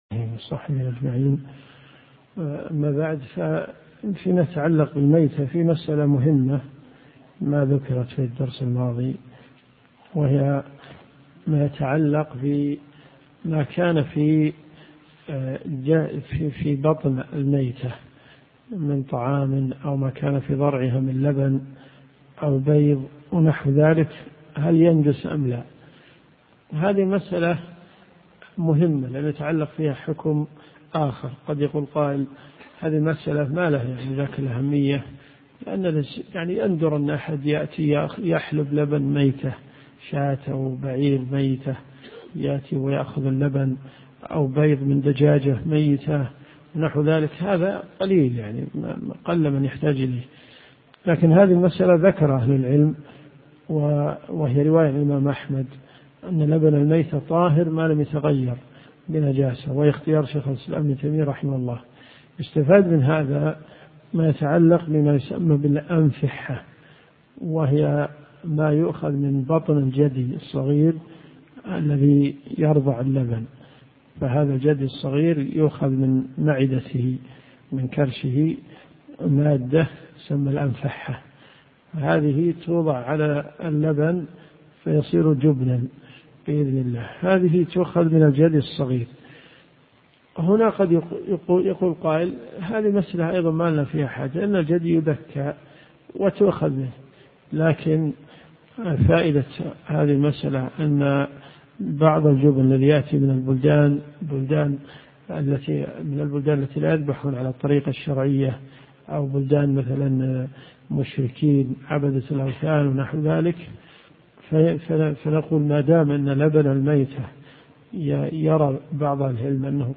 الرئيسية الكتب المسموعة [ قسم الفقه ] > منار السبيل .